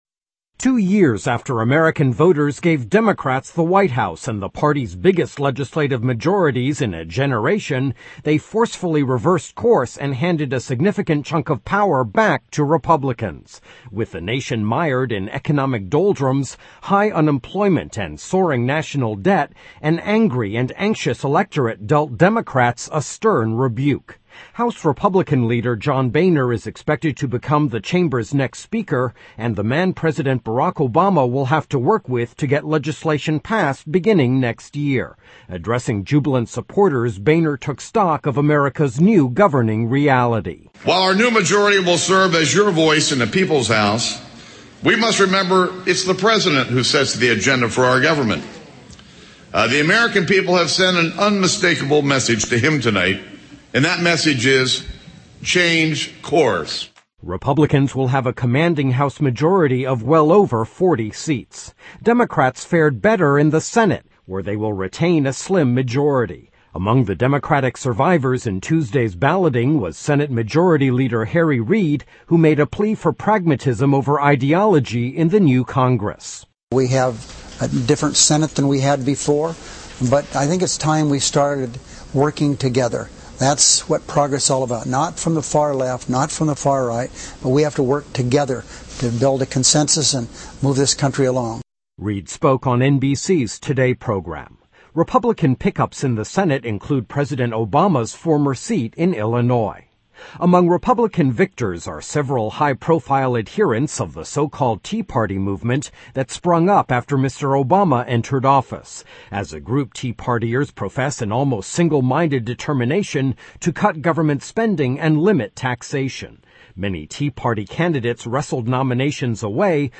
report on US Elections 3 Nov 2010